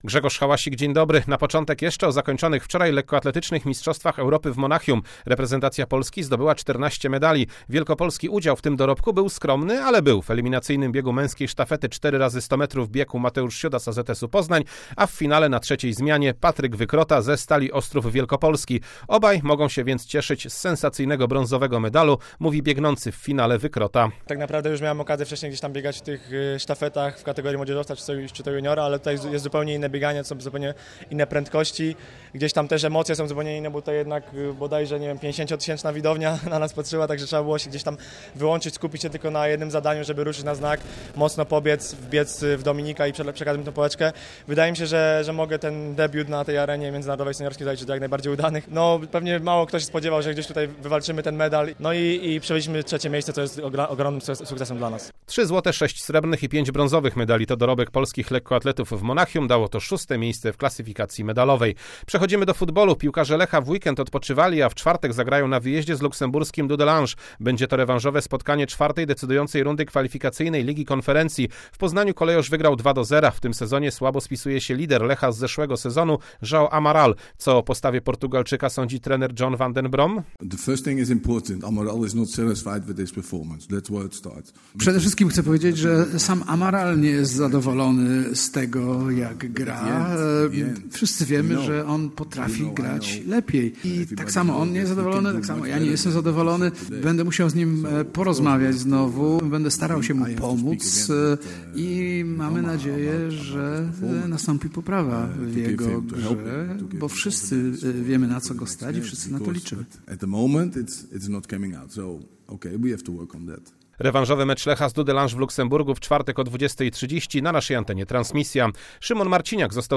22.08.2022 SERWIS SPORTOWY GODZ. 19:05